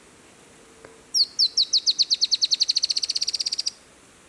Tiluchi Estriado (Drymophila malura)
Nombre en inglés: Dusky-tailed Antbird
Condición: Silvestre
Certeza: Fotografiada, Vocalización Grabada